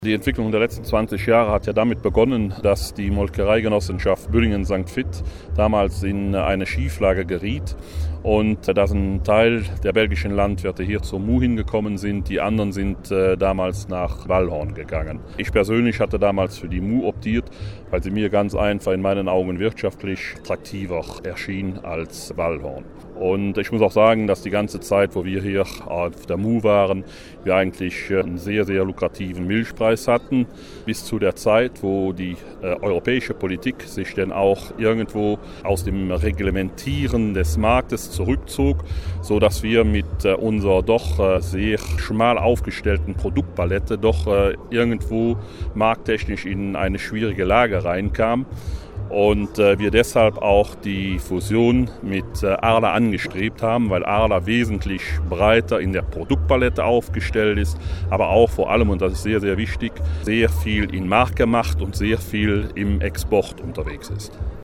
sprach mit Landwirt